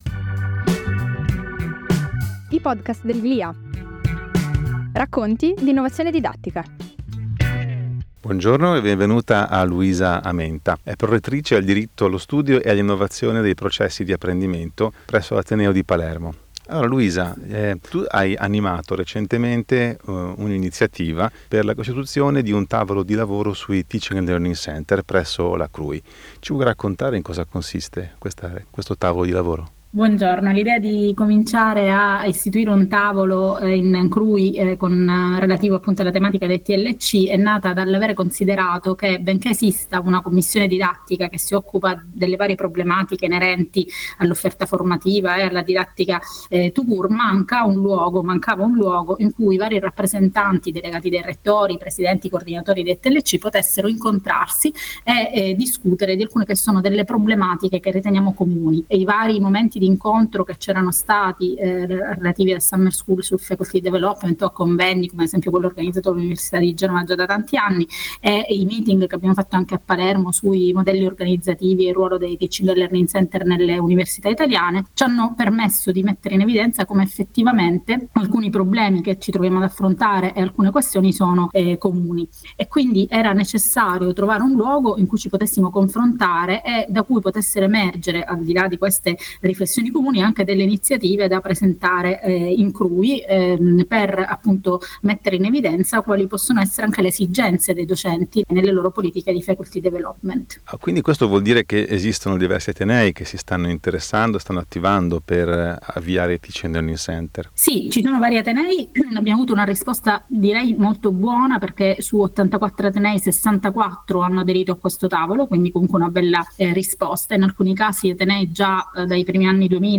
5° convegno sul Faculty Development
Intervista